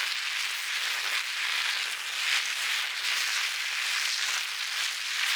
small_wheel.wav